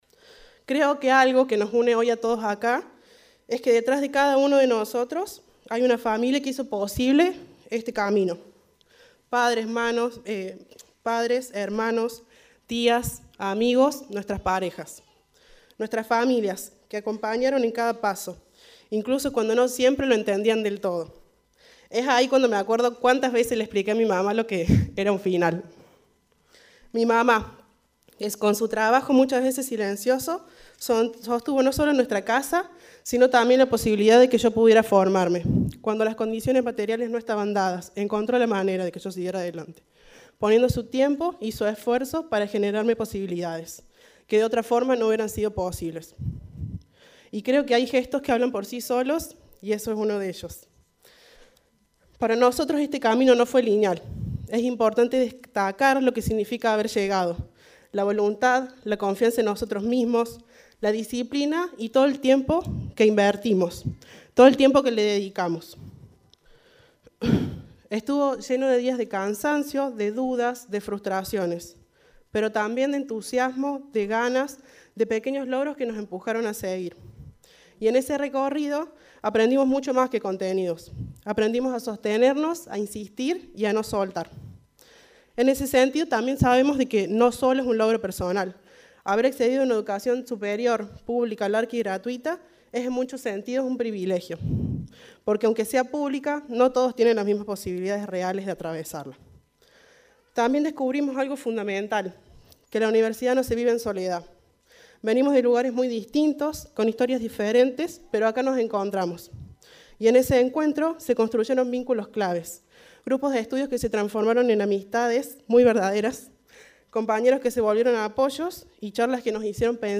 Se realizó una nueva colación en la UNRC
El acto se realizó en dos ceremonias en el aula mayor del campus.